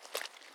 Water Walking 1_03.wav